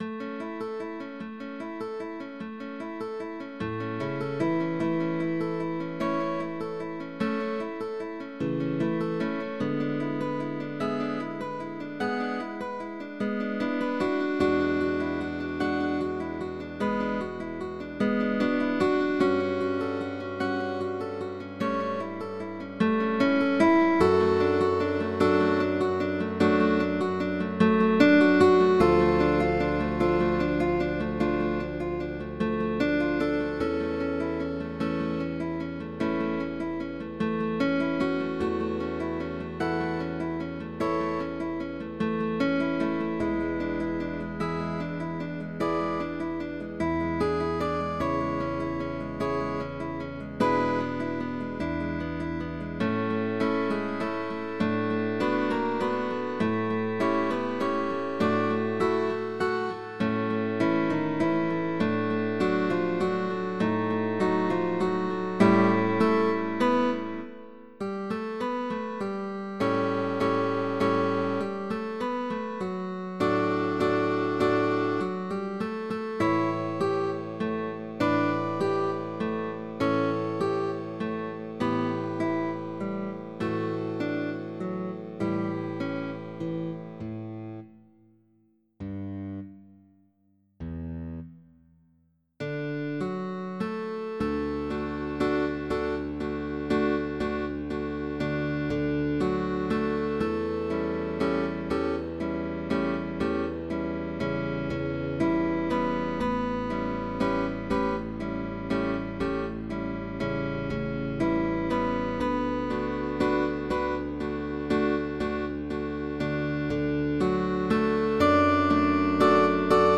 GUITAR ORCHESTRA
a waltz